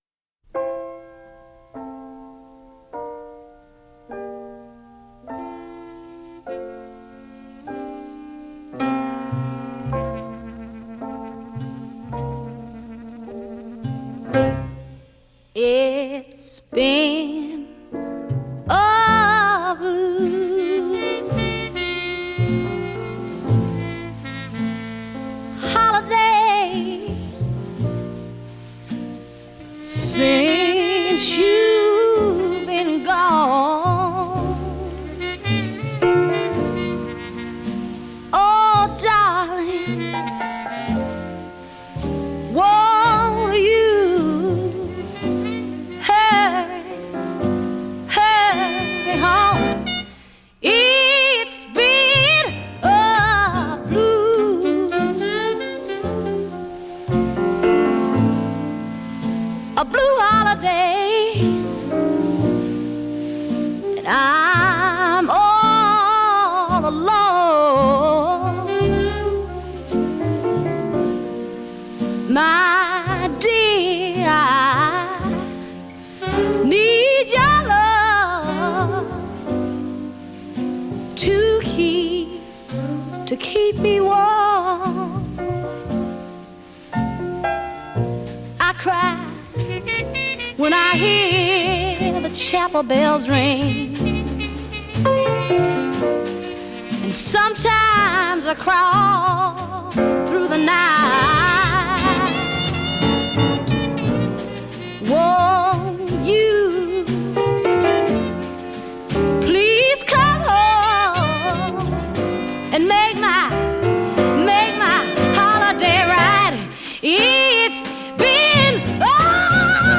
آلبوم جاز/سول
Soul, Jazz